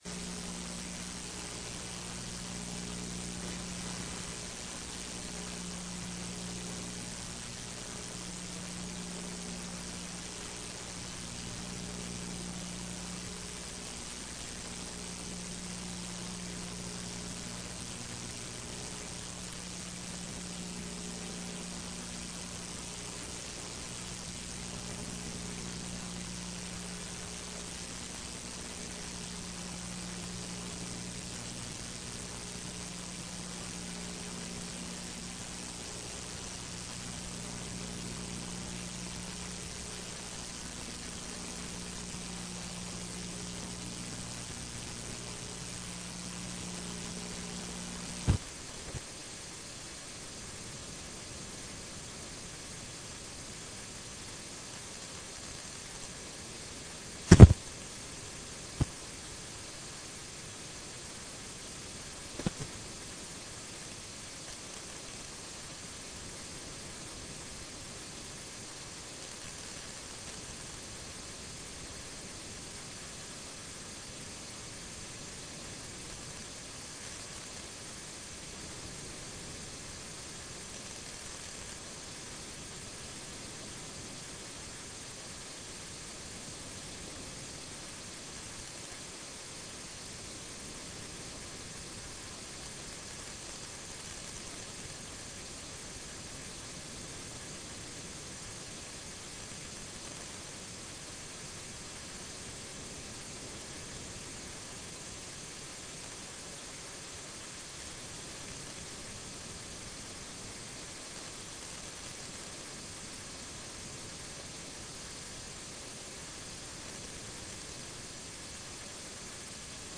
Áudio da sessão plenária do dia 25/02/15